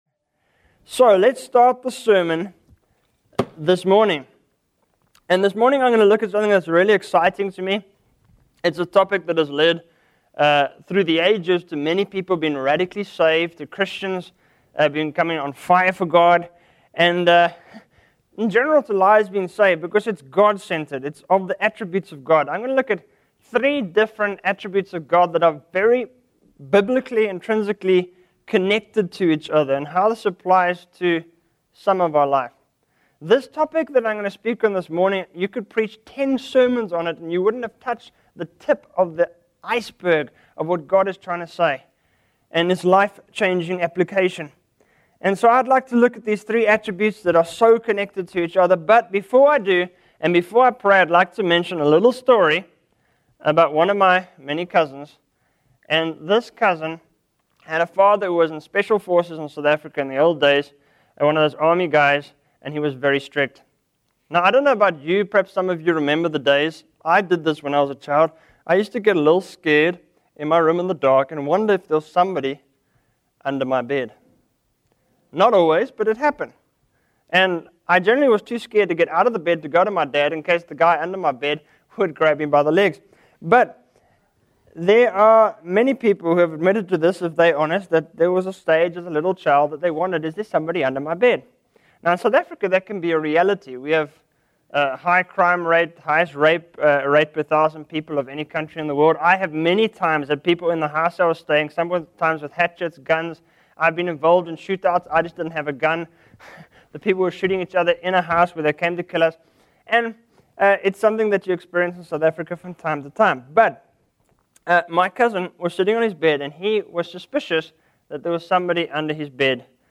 In this sermon, the preacher discusses three attributes of God that are interconnected and have the power to radically transform lives. The sermon begins with a personal anecdote about a child who is afraid of someone being under their bed, highlighting the unexpected dangers that can lurk in the darkness. The preacher then leads into a prayer, acknowledging God's holiness, love, and unchanging nature.